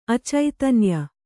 ♪ acaitanya